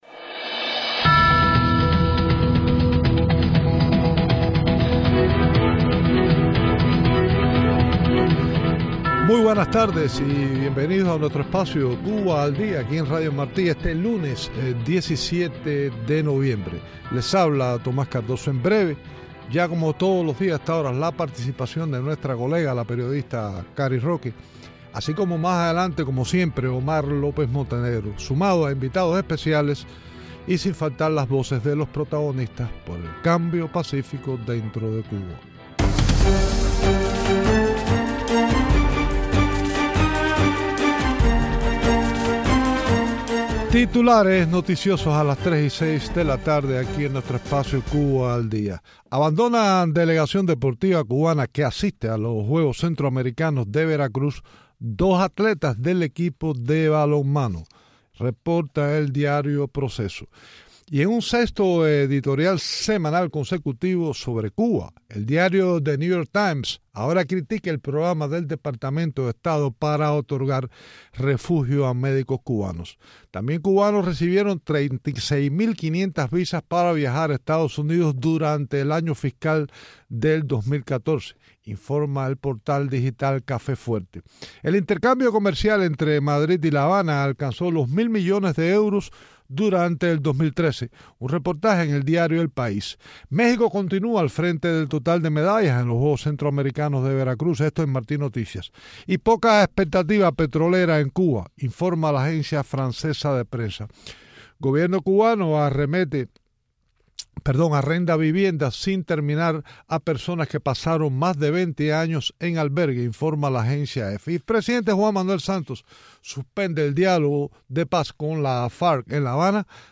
Entrevistas con embajador Myles Frechette y Berta Soler en Cuba.